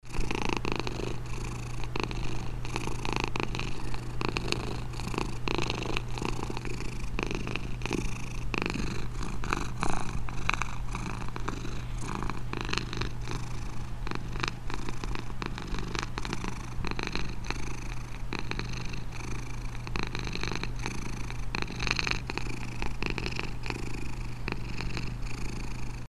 • Качество: 320, Stereo
звук кошки
мурчание
Приятный кошачий "храп"